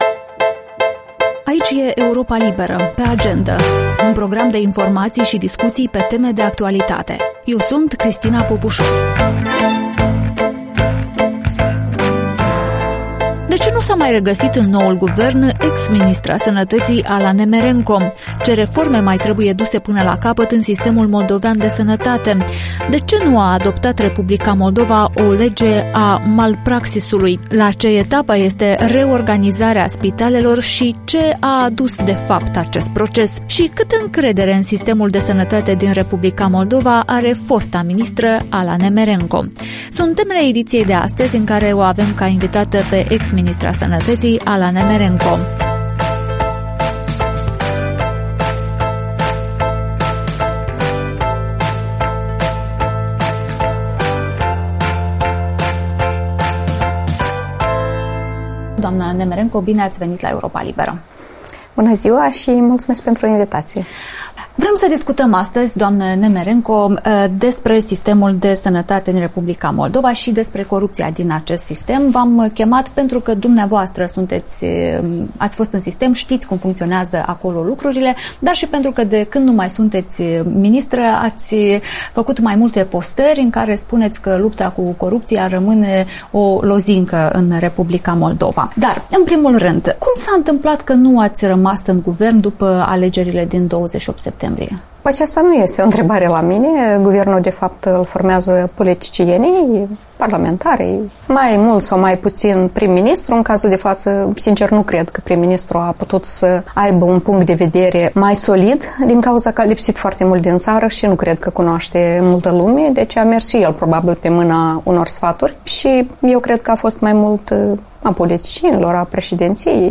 În această ediție a podcastului „Pe Agendă”, fosta ministră a Sănătății, Ala Nemerenco, explică de ce nu s-a regăsit în guvernul condus de Alexandru Munteanu.